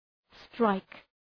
Προφορά
{straık}